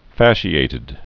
(făshē-ātĭd) also fas·ci·ate (-āt)